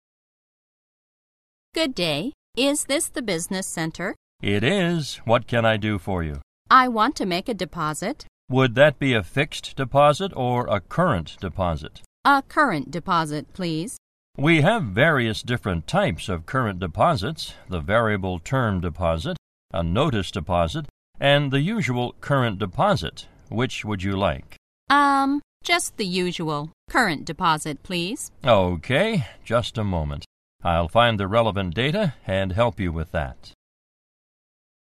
在线英语听力室银行英语情景口语 第5期:现金业务 存款情景(1)的听力文件下载, 《银行英语情景口语对话》,主要内容有银行英语情景口语对话、银行英语口语、银行英语词汇等内容。